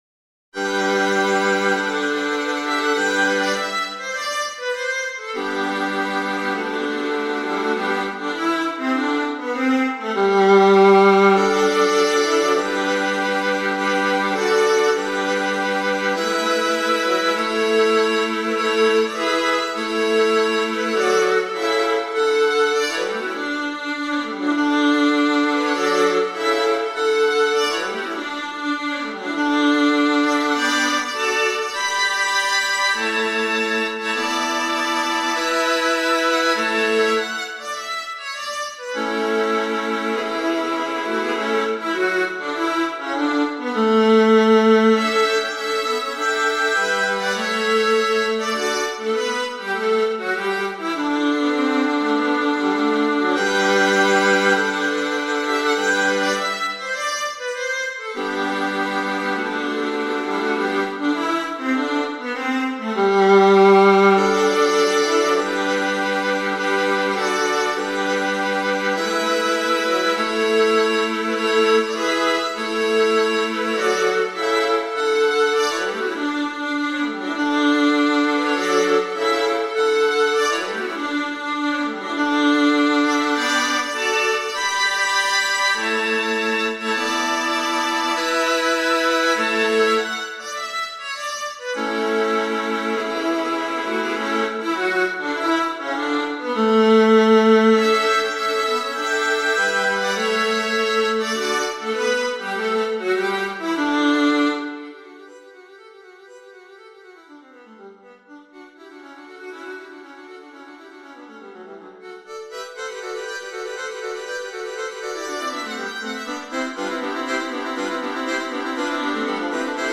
viola quartets